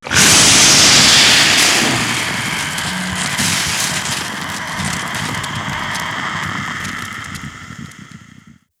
Vapor de una plancha 03
vapor
Sonidos: Hogar